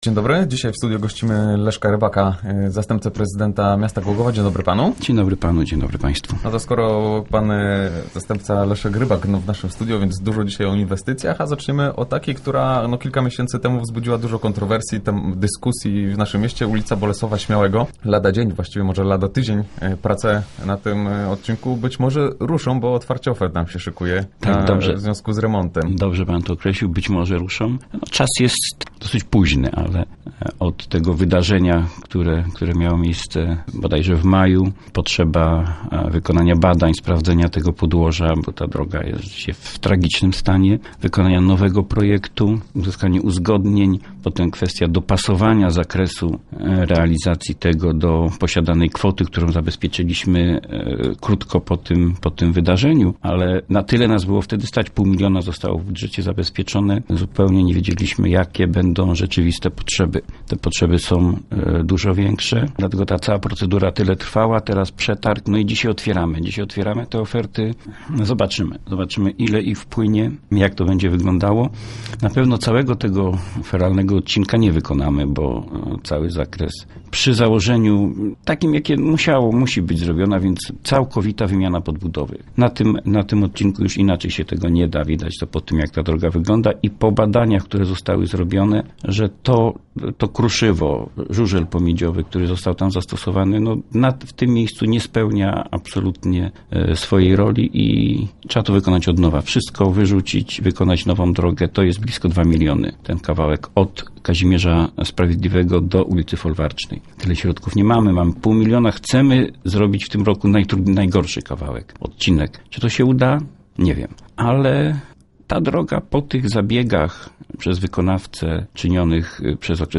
Wyłaniany jest właśnie wykonawca częściowego remontu, poszkodowani kierowcy zawierają też ugody z wykonawcą feralnej drogi. Między innymi o tym rozmawialiśmy z Leszkiem Rybakiem, zastępcą prezydenta miasta.